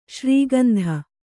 ♪ śrī gandha